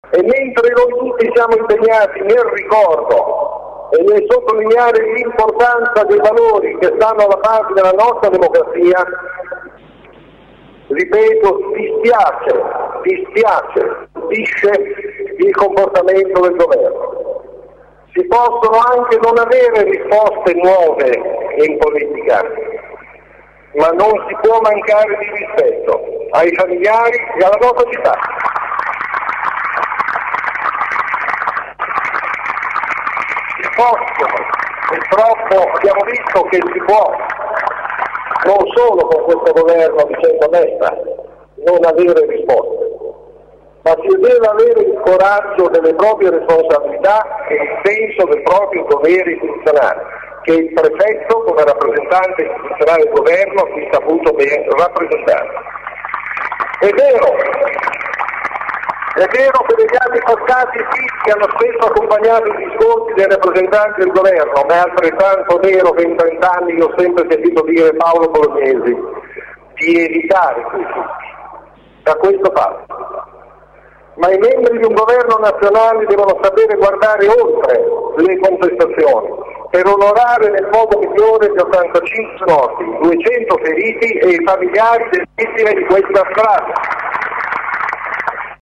Sindaco_2_agosto.mp3